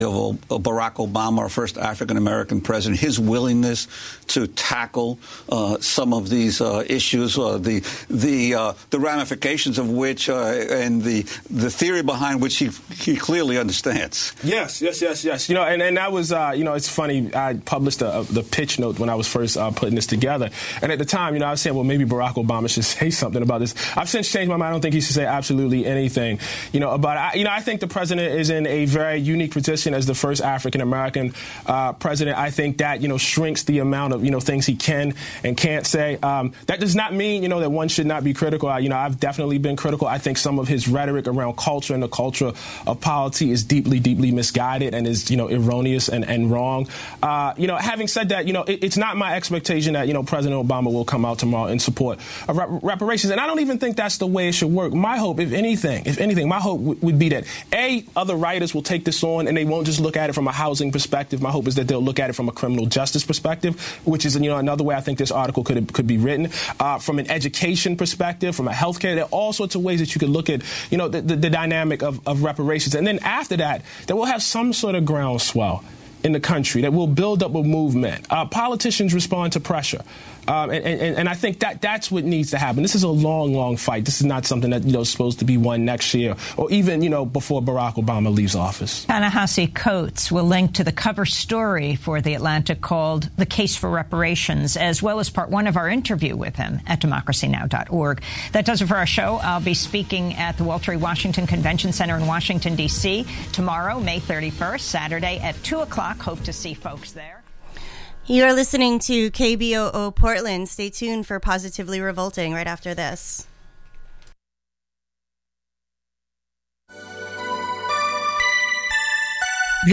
live in the studio to take your calls